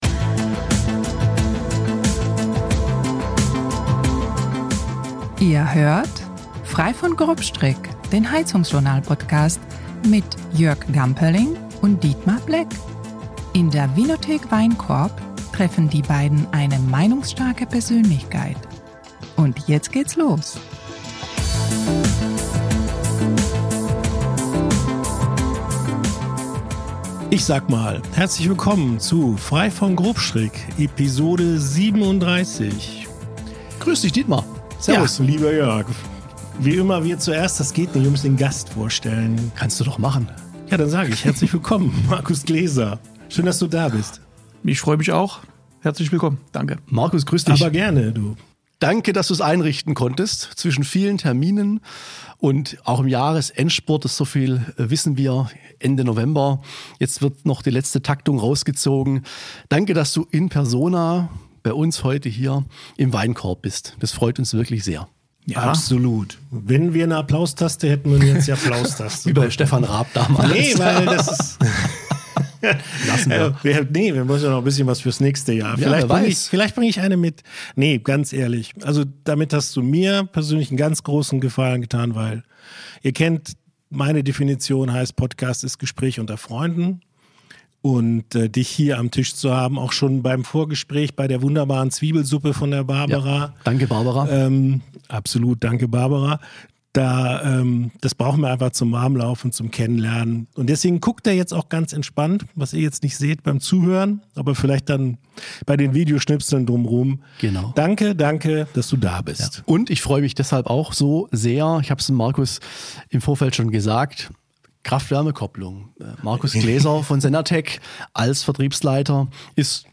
Danach geht es bestens gestärkt auf die Bühne, wo der Tisch mit den Mikros schon wartet.
Locker bis launig, meinungsstark und informativ, das ist Frei von Grobstrick, der HeizungsJournal- Podcast.